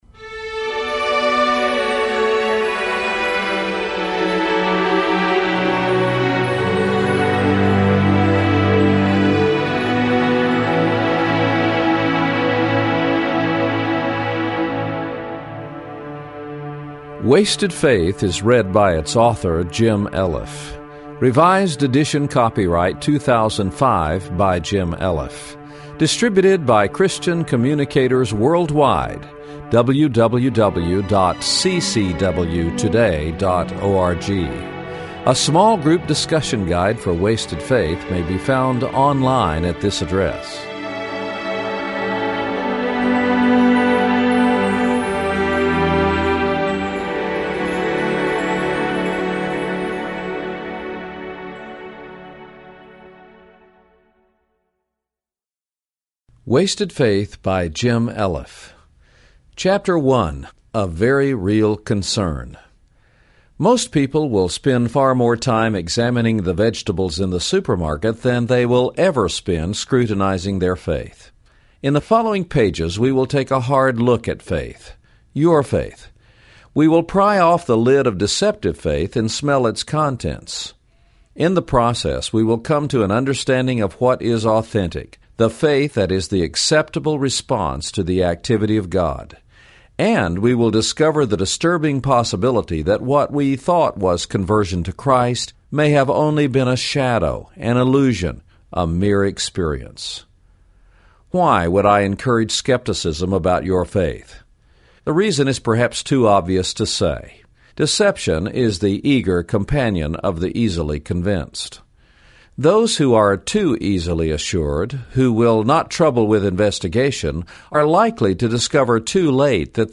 Pursuing God Audio Book Wasted Faith Audio Book Genealogies Video The Eaglet Video
Wasted-Faith-Full-Book-64kbps.mp3